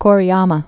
(kôrēmə, -yämä)